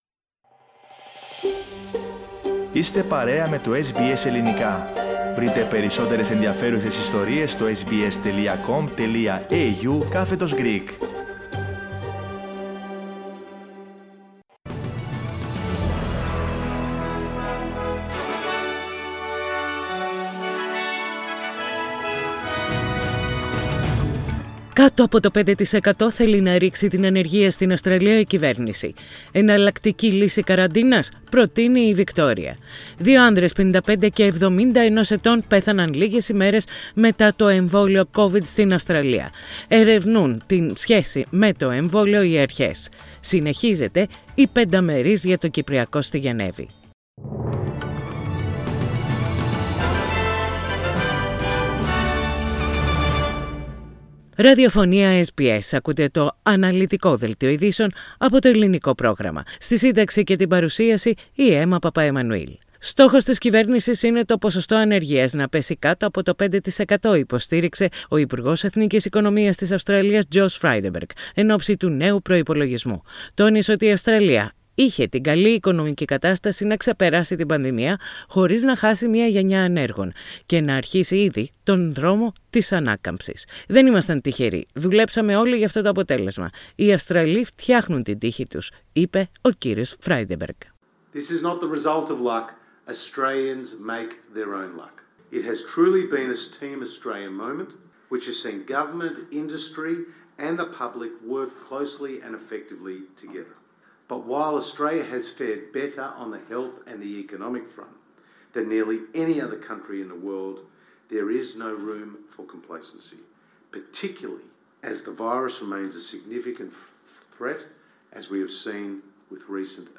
Οι κυριότερες ειδήσεις της ημέρας από το Ελληνικό πρόγραμμα της ραδιοφωνίας SBS.